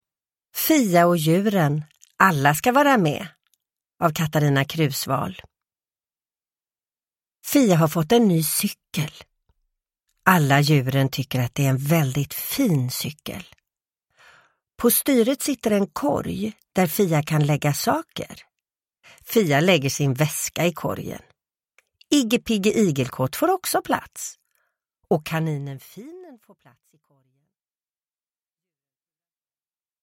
Fia och djuren : alla ska vara med! – Ljudbok – Laddas ner
Uppläsare: Sissela Kyle